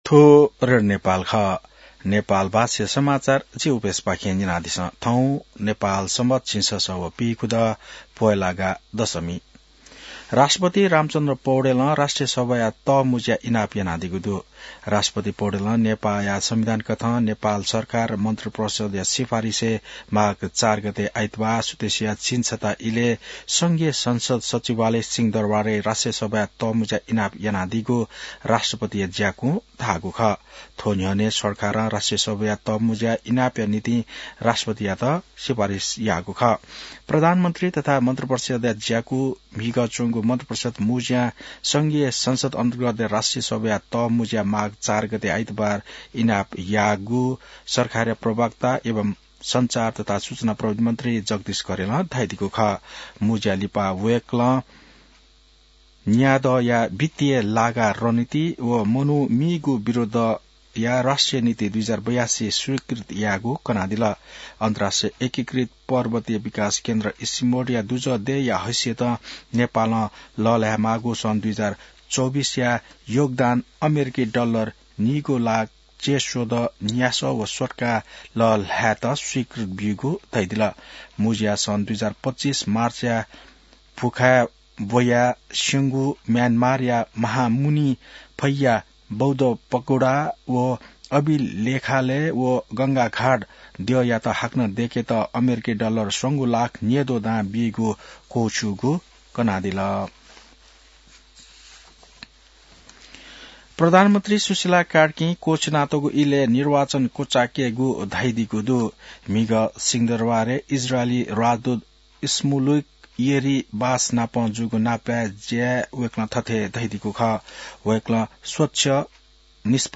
नेपाल भाषामा समाचार : २९ पुष , २०८२